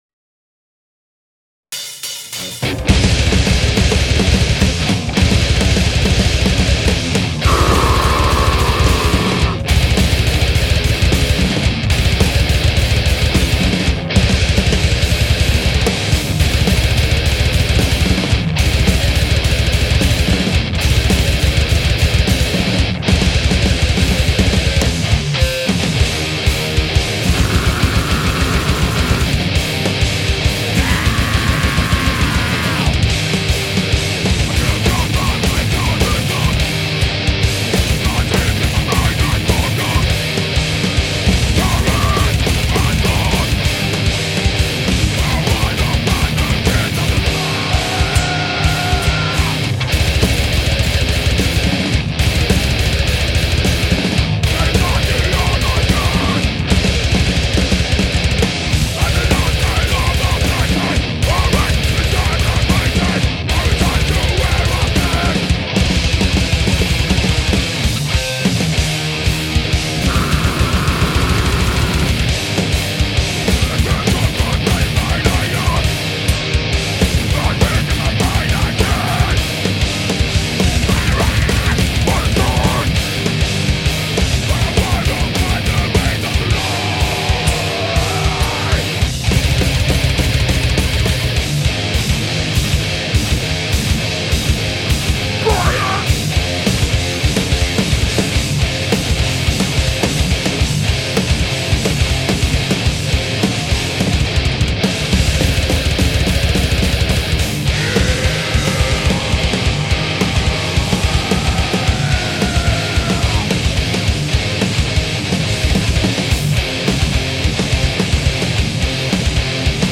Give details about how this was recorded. Cover Live enregistrée le 31/05/2005 à l' Espace Curial à Paris